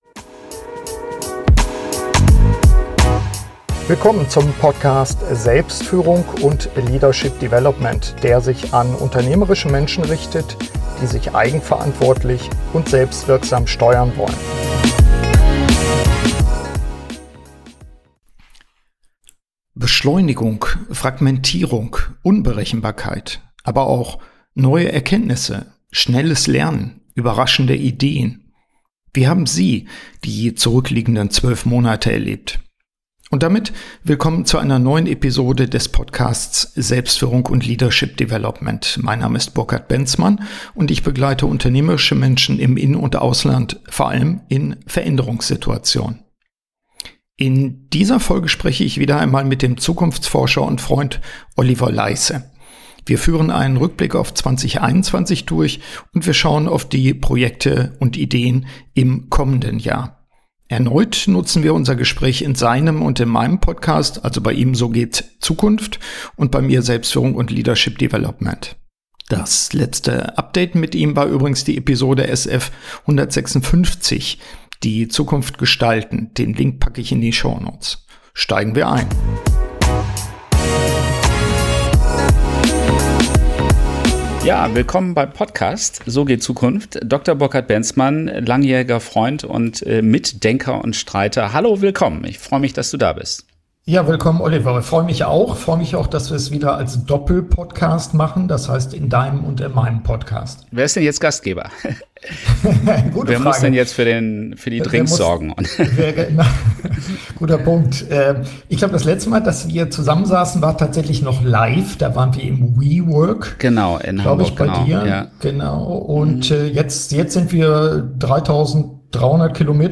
Im Gespräch mit dem Zukunftsforscher erörtern wir die Phänomene Beschleunigung, Fragmentierung und Unberechenbarkeit. Wir betrachten nicht nur die negativen Aspekte, sondern entdecken auch positive Aspekte, wie z.B. schnelleres Lernen.